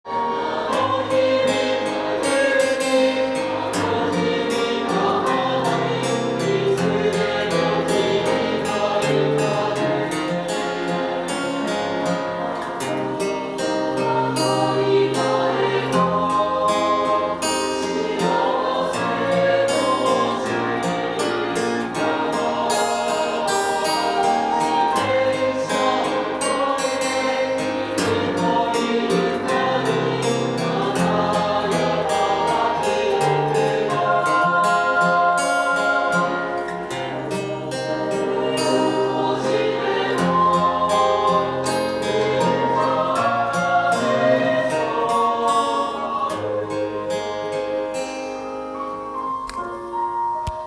アルペジオtab譜
音源にあわせて譜面通り弾いてみました
カポタストを１フレットにつけると譜面通り弾くことが出来ます。
コード進行は、明るいメジャーコードから始まりますが
クリシェと言われる半音階のベースラインなど